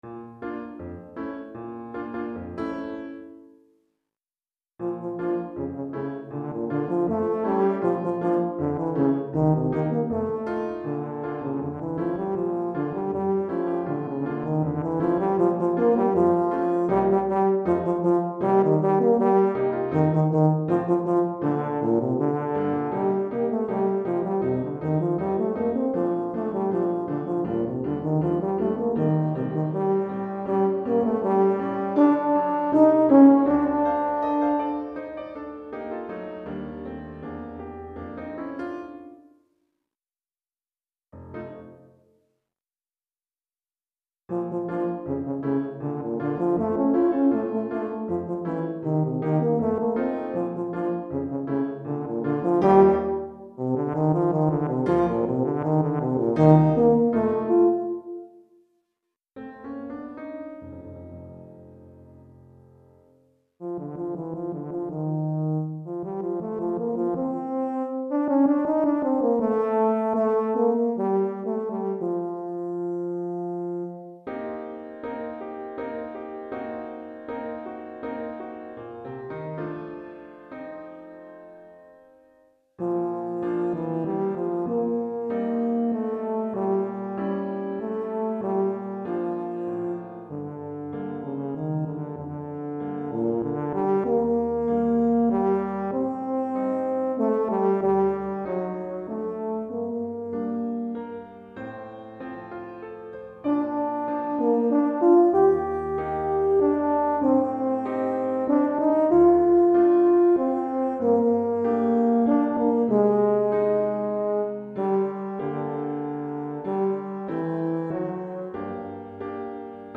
Répertoire pour Tuba, euphonium ou saxhorn